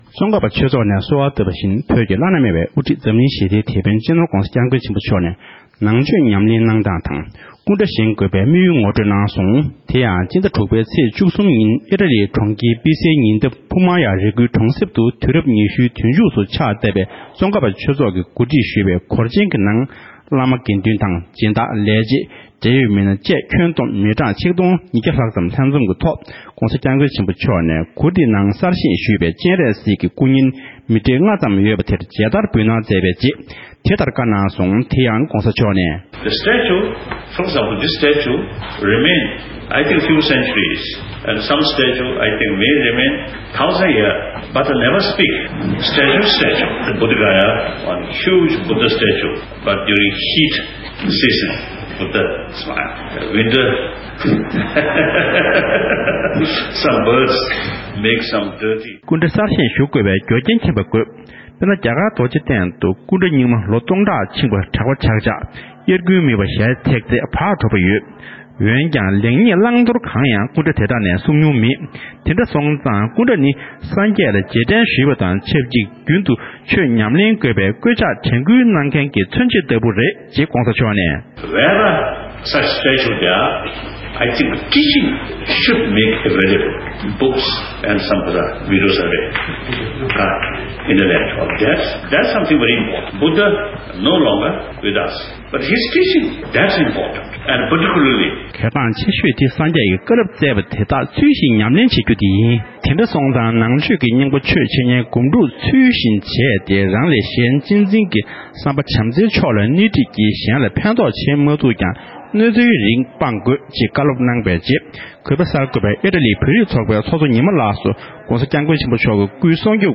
སྒྲ་ལྡན་གསར་འགྱུར།
གསར་འགོད་པ